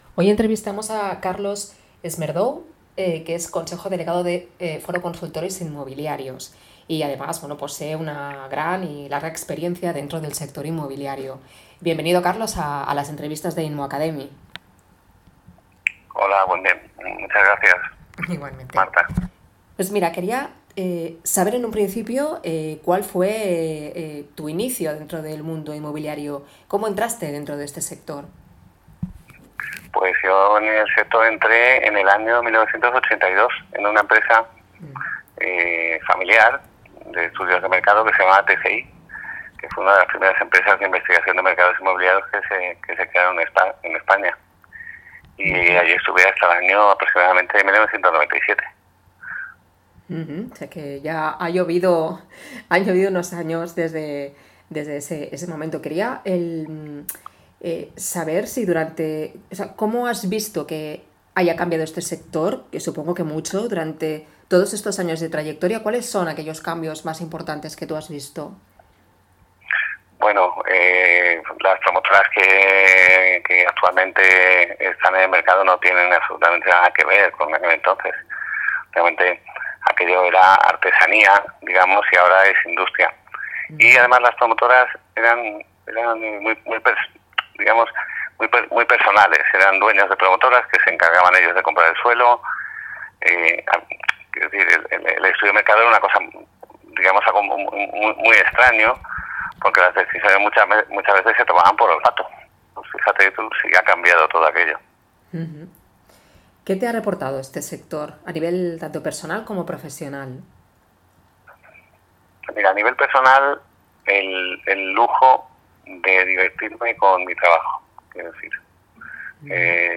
ACCEDE A LA ENTREVISTA COMPLETA EN INMOACADEMY ¿Te ha gustado el artículo?